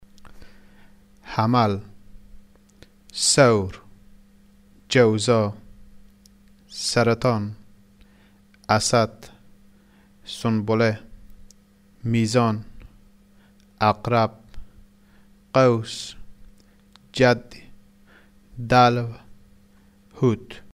(You can listen to the month names as pronounced in two cities in Afghanistan.)
Dialect of Herat
Dari-Herat.mp3